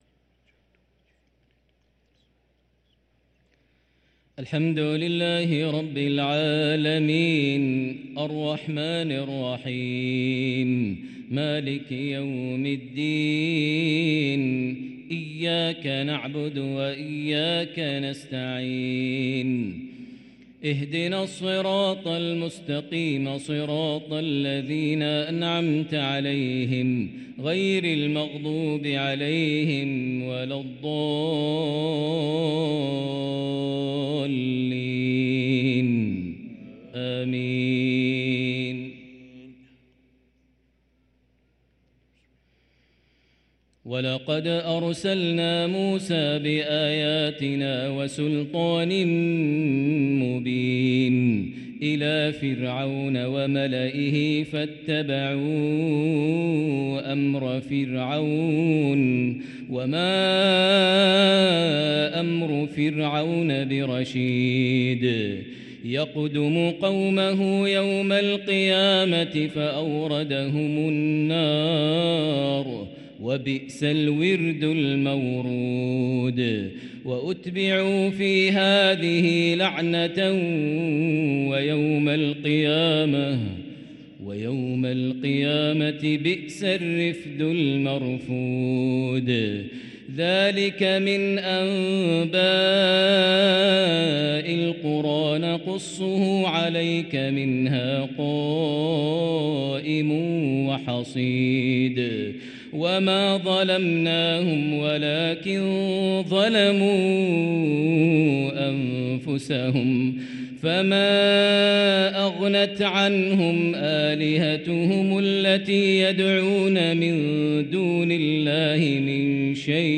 صلاة العشاء للقارئ ماهر المعيقلي 17 شعبان 1444 هـ
تِلَاوَات الْحَرَمَيْن .